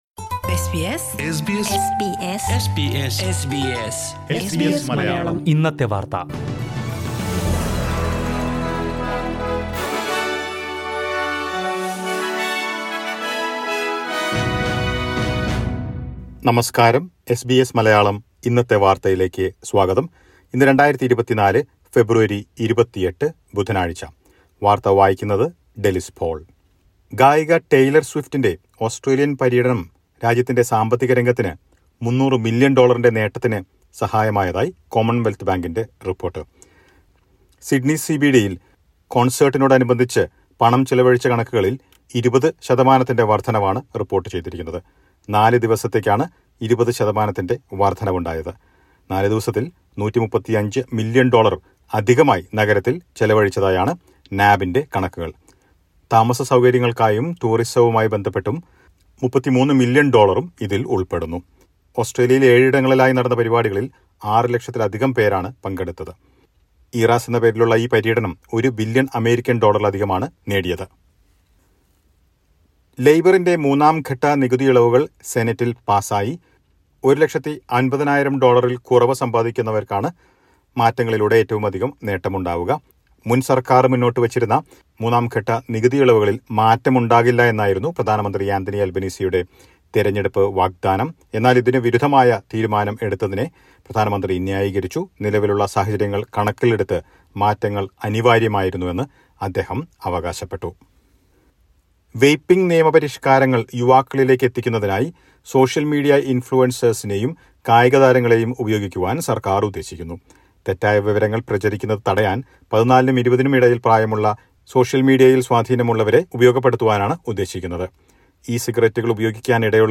2024 ഫെബ്രുവരി 28ലെ ഓസ്ട്രേലിയയിലെ ഏറ്റവും പ്രധാന വാർത്തകൾ കേൾക്കാം...